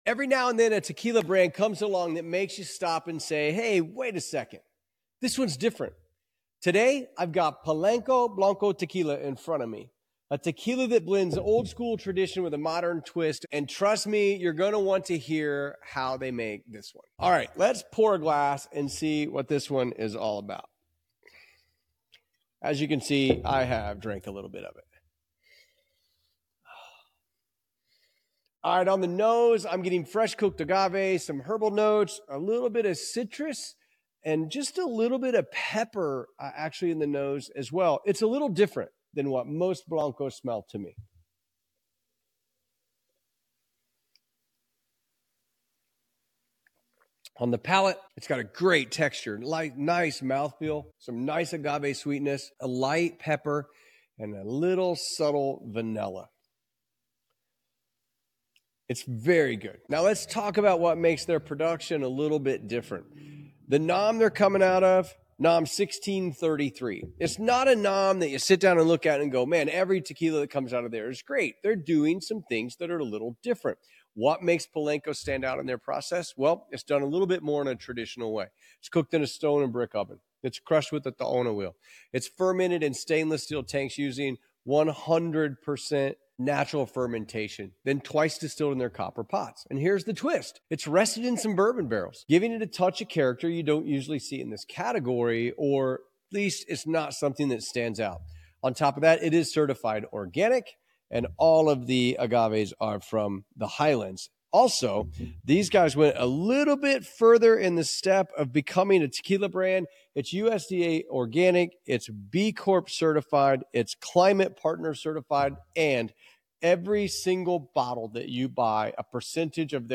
What Makes Polanco Tequila Special | Blanco Review